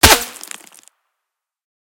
Better Bullet Cracks
bulletFlyBy_3.ogg